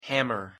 Pronunciation En Hammer